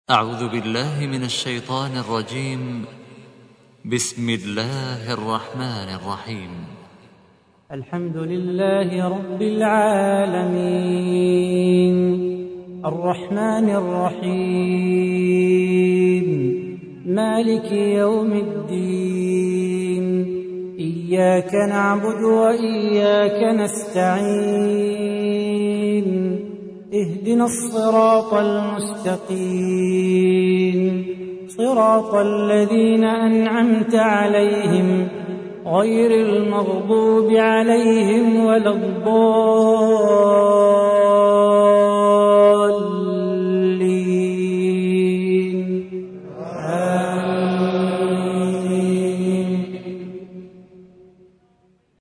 تحميل : 1. سورة الفاتحة / القارئ صلاح بو خاطر / القرآن الكريم / موقع يا حسين